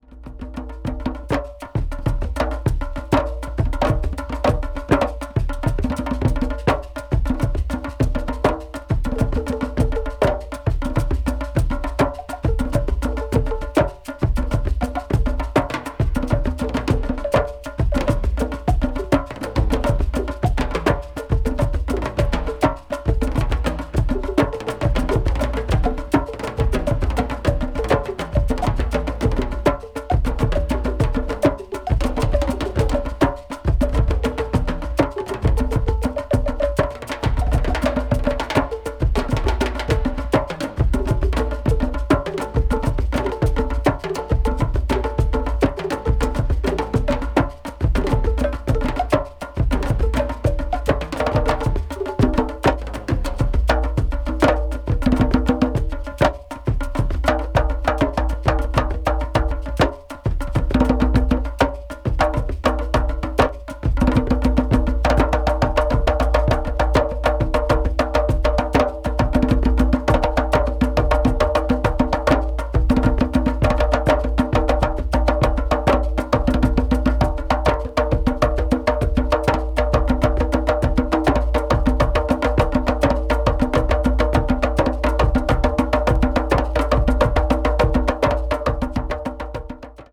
media : EX/EX(わずかにチリノイズが入る箇所あり)
africa   ethnic   percussion   primitive   world music